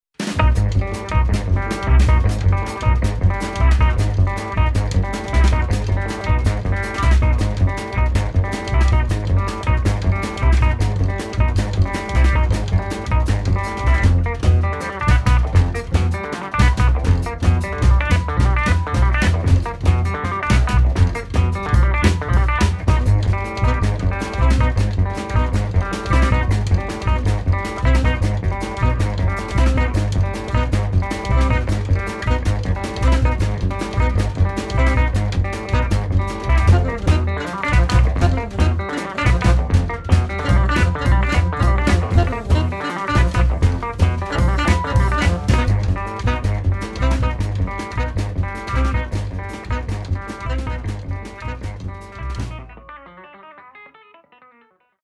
guitar
sax, flute
bass
drums